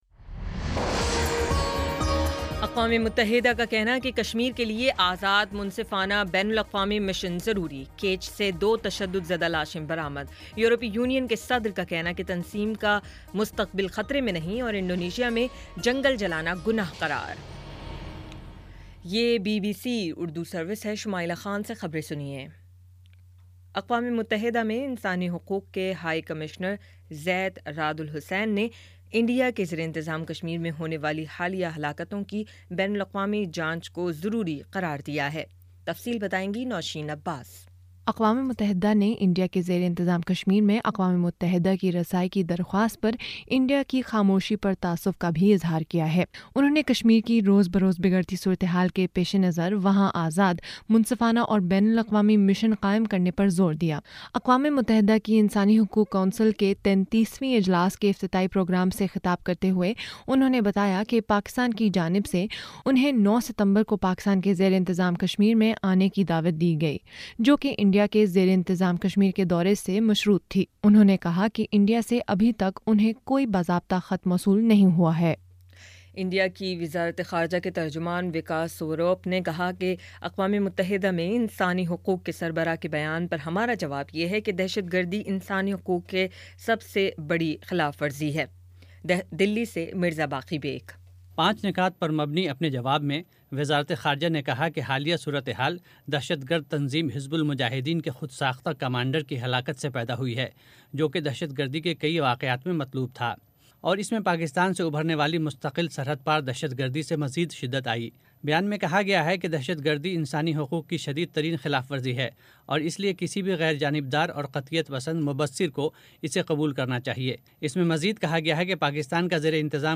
ستمبر 14 : شام پانچ بجے کا نیوز بُلیٹن